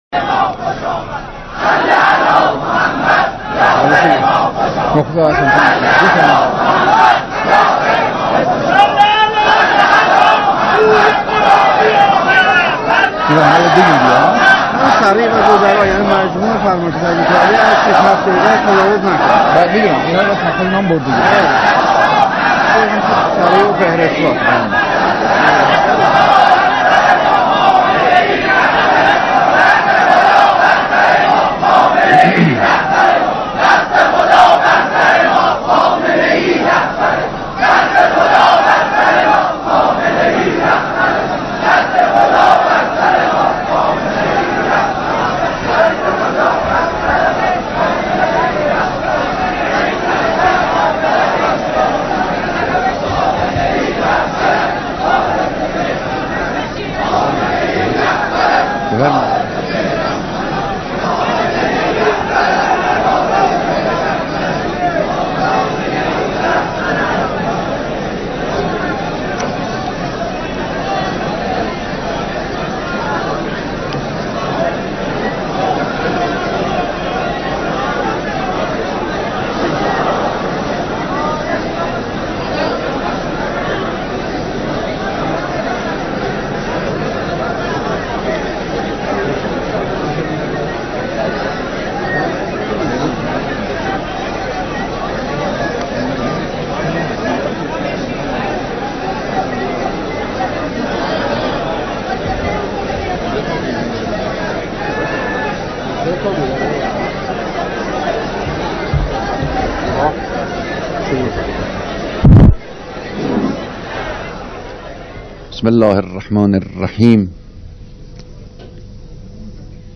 بیانات رهبر انقلاب در دیدار اقشار مختلف مردم